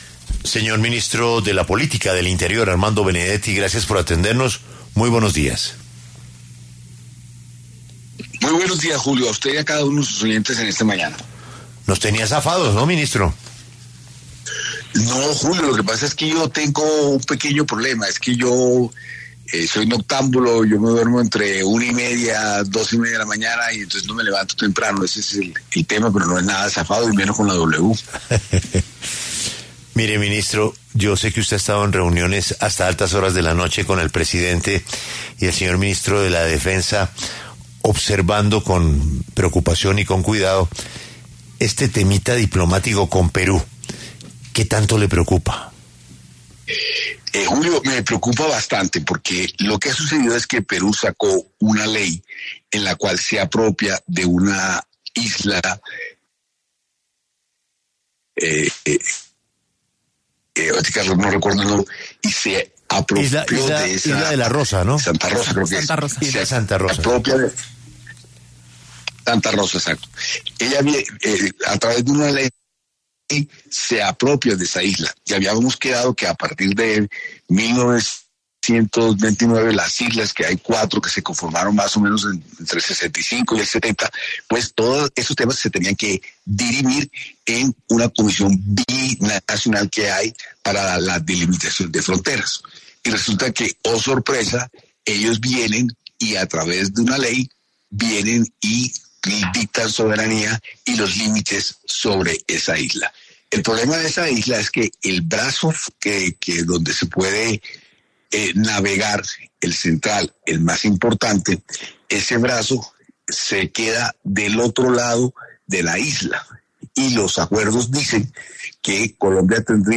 El ministro del Interior, Armando Benedetti, pasó por los micrófonos de La W para hablar sobre distintos temas que le competen a su cartera y al Gobierno Nacional.